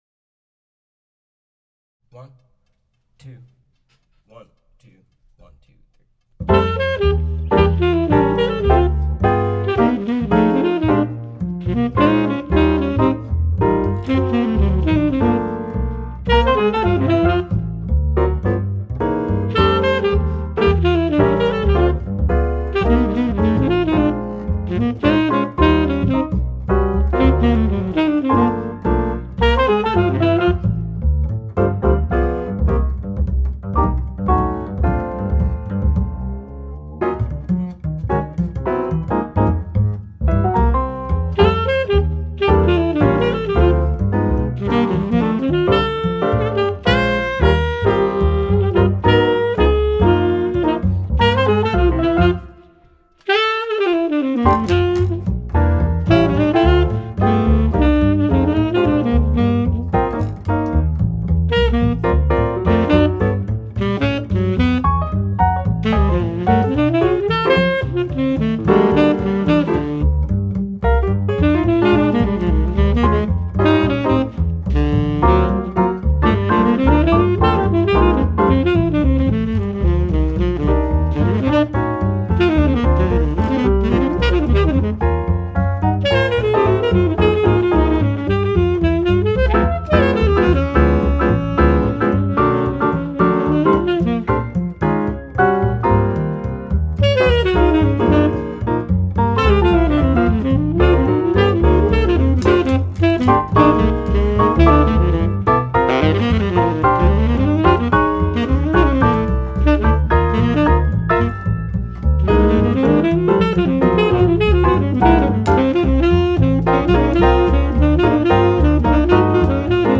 Play Along (without Drums)